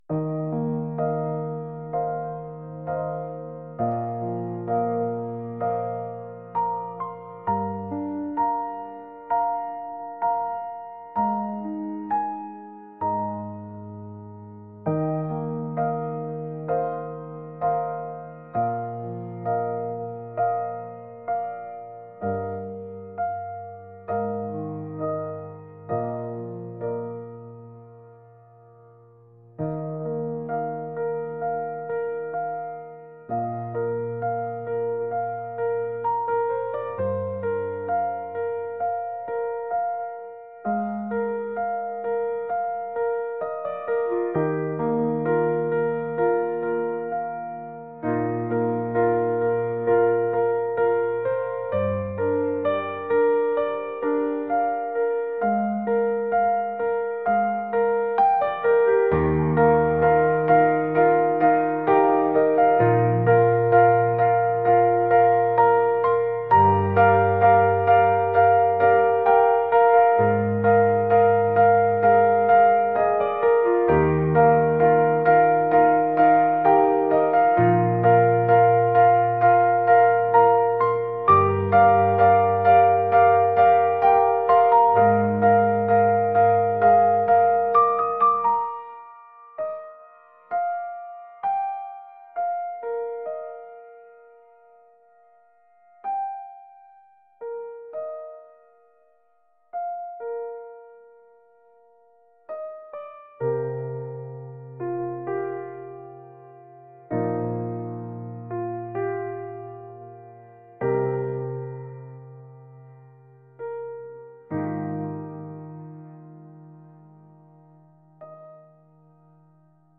pop | dreamy | ethereal